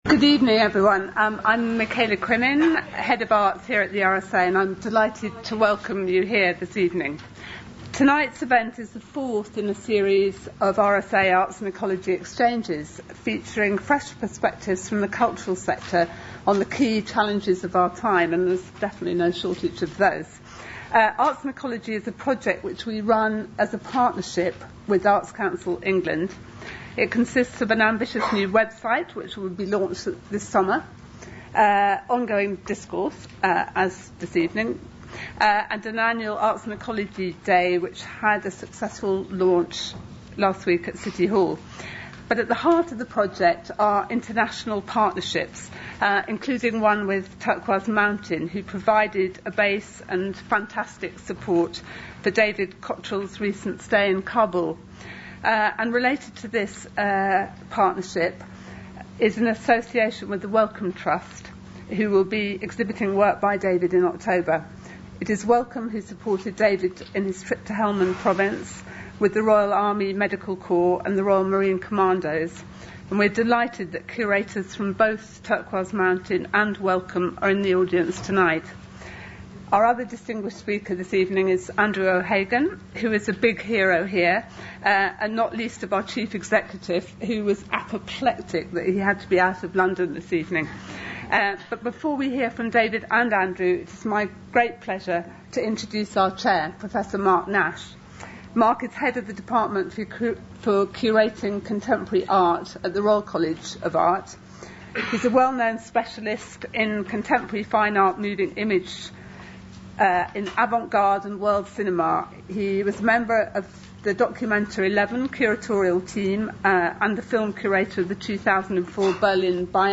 RSA Lecture : Culture in a Time of Conflict
An MP3 podcast recording of the lecture delivered at the RSA reflecting on the contradictions witnessed during two visits to Afghanistan.